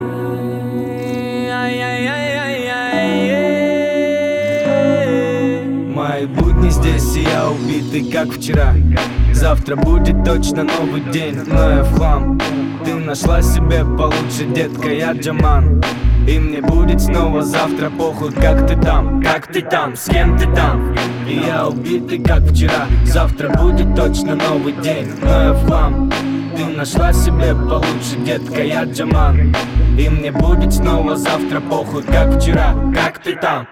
• Качество: 320, Stereo
восточные мотивы
растаманские